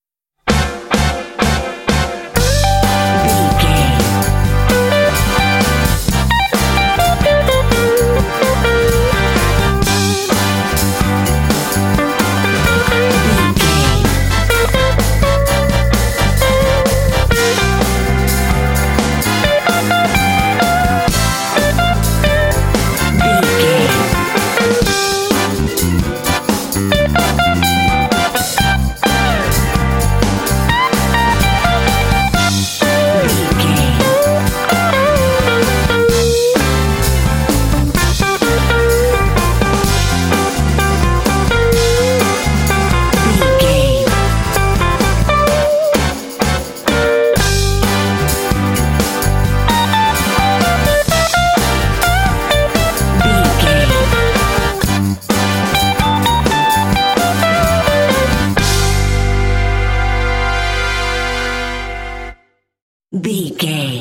Aeolian/Minor
intense
driving
energetic
groovy
funky
electric guitar
electric organ
bass guitar
drums
brass
Funk
blues